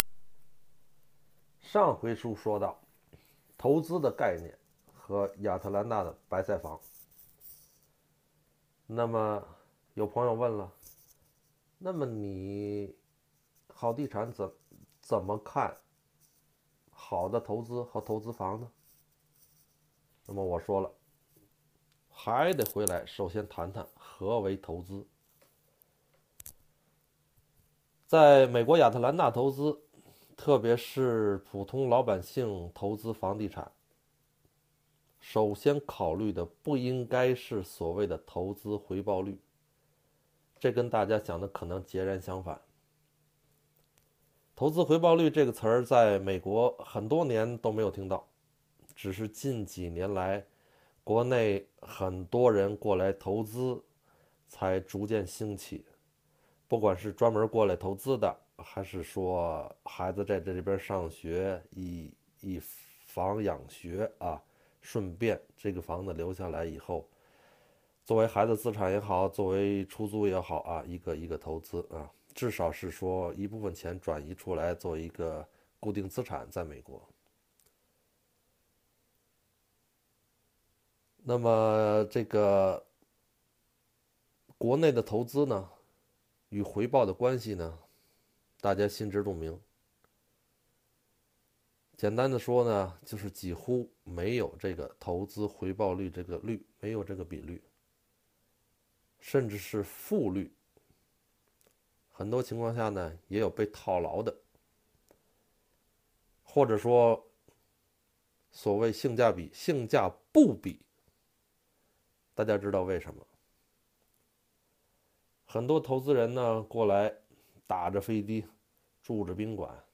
【语音讲座】投资房(2)